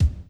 上記動画の1:08頃から始まるバスドラムの音を考えてみよう．
drum2.wav